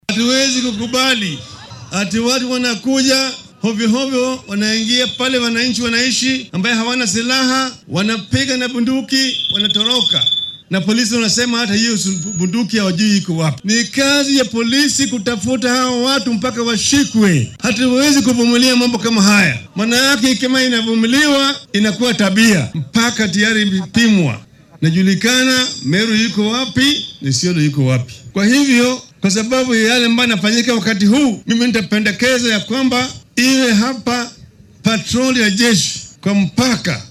Ra’iisul wasaarihii hore ee Kenya oo ka qayb galay aaska toddoobadii qof ee la dilay oo lagu qabtay deegaanka bariga Tigania ee ismaamulka Meru ayaa shacabka ka codsaday inay si